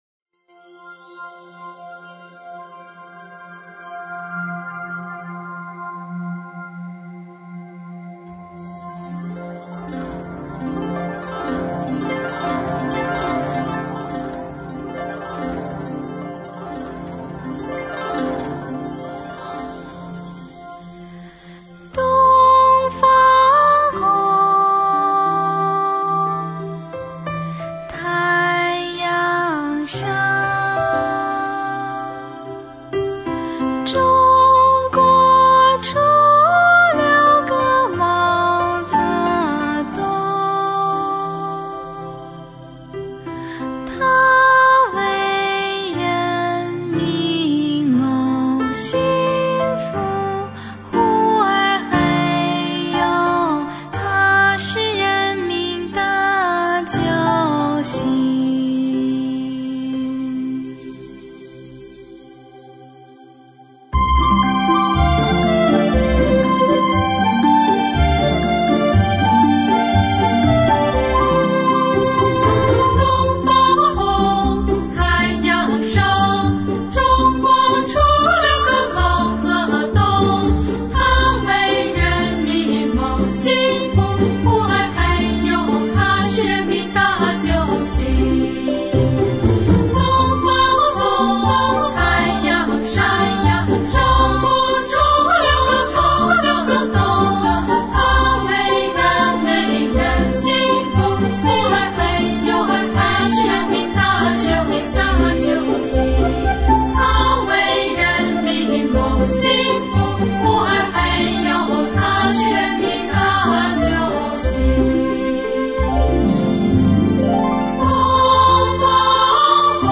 陕北民歌